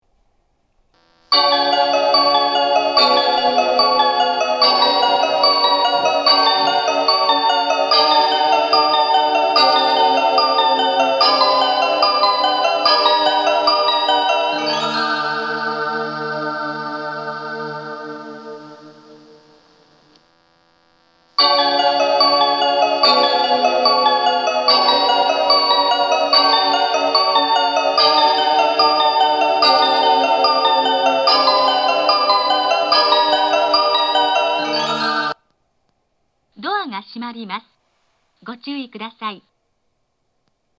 発車メロディー
1.9コーラスです!曲が長いので途中切りが多いです。
収録当時、振り替え輸送の関係で大変混雑しておりました。